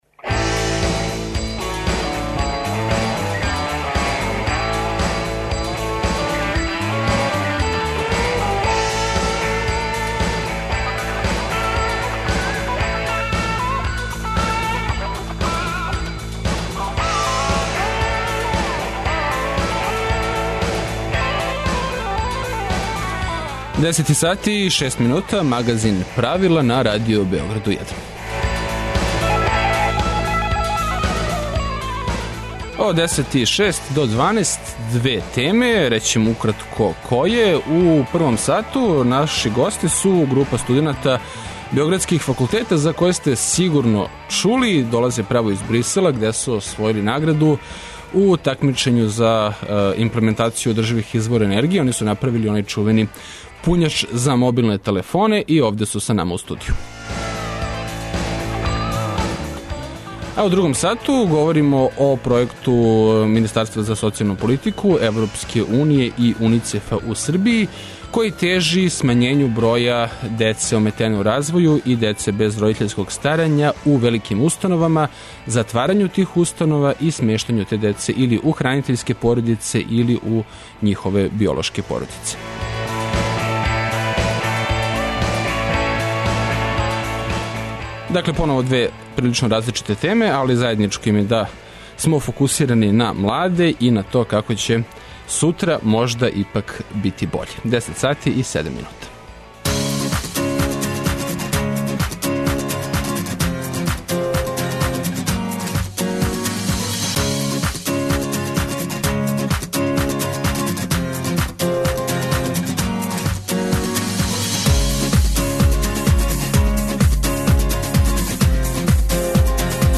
У госте Радио Београду стижу директно из Брисела.